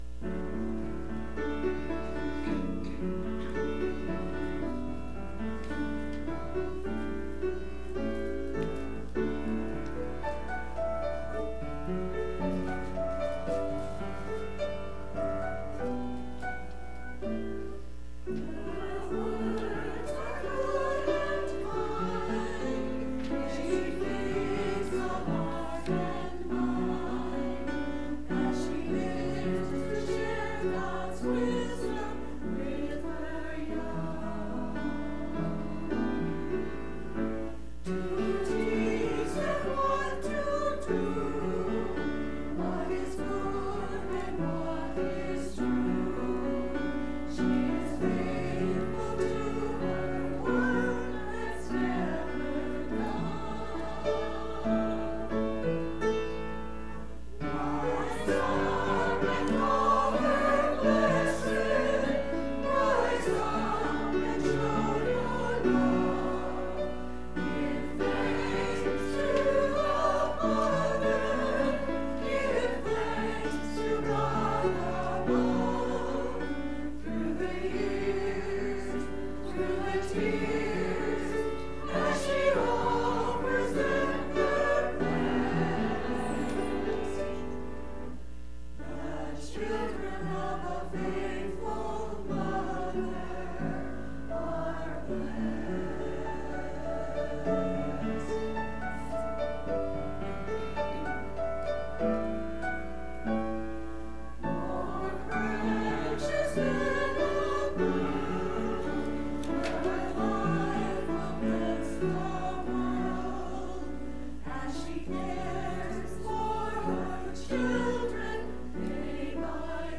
Church Choir – Rise up and call her blessed 05.12.19 | Good Shepherd Lutheran Church
Church Choir – Rise up and call her blessed 05.12.19
To hear the church choir praise God with music please click below.